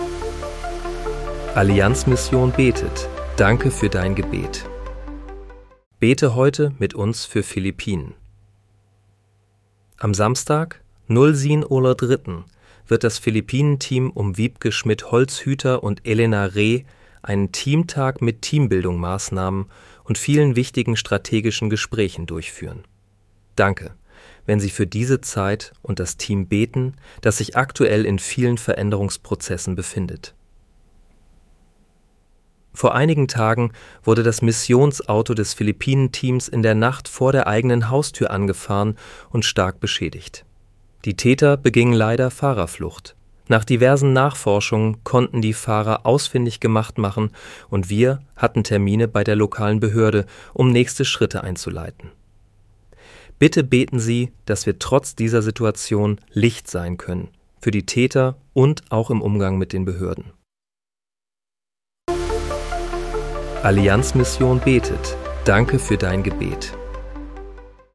Bete am 04. März 2026 mit uns für Philippinen. (KI-generiert mit